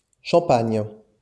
Champagne (French pronunciation: [ʃɑ̃paɲ]